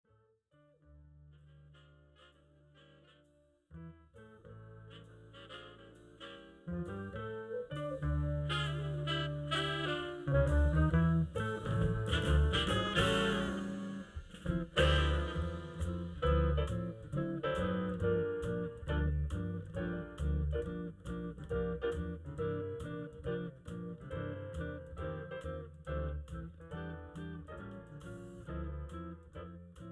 instrumental backing track cover
• Without Backing Vocals
• No Fade